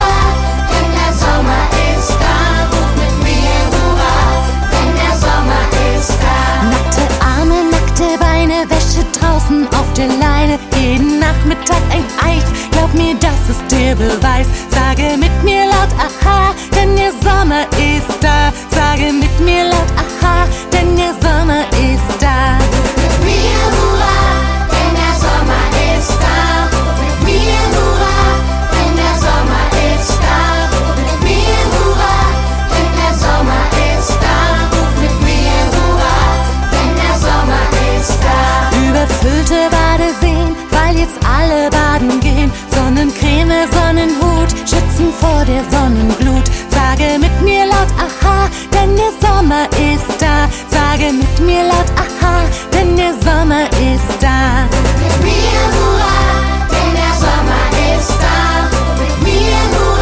• Sachgebiet: mp3 Kindermusik